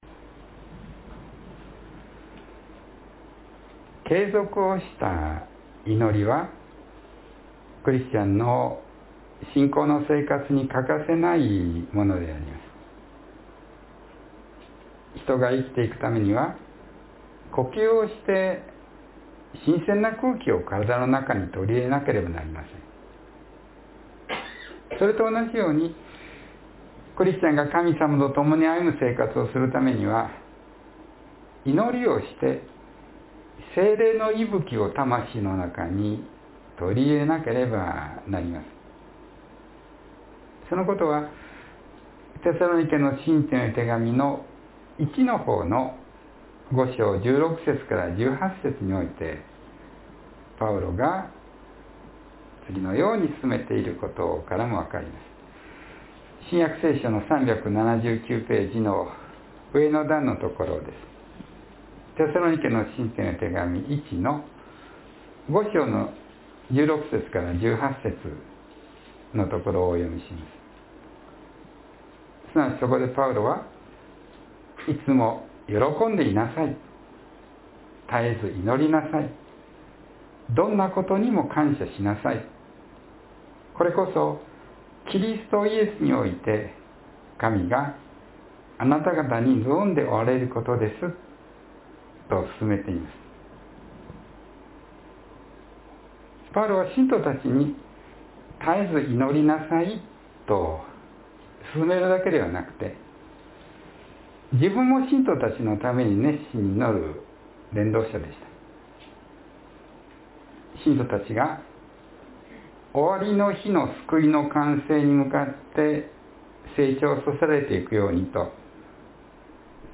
（12月18日の説教より）